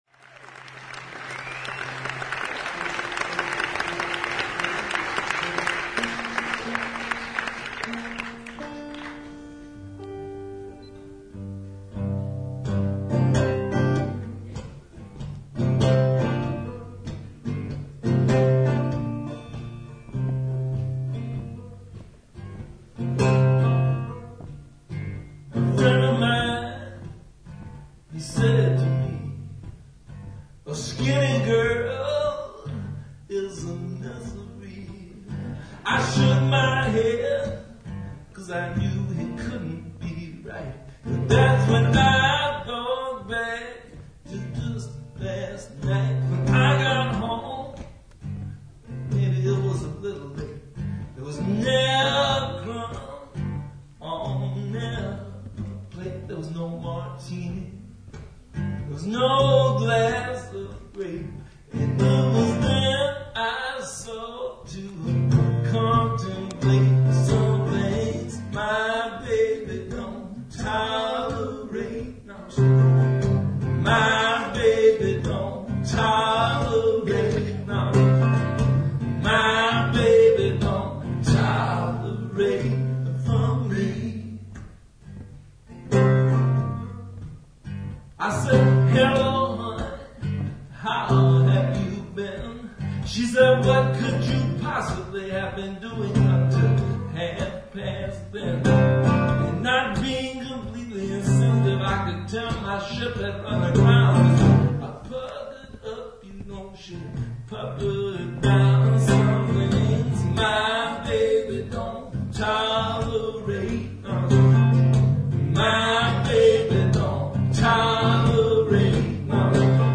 Zürich, Feb 1, 2010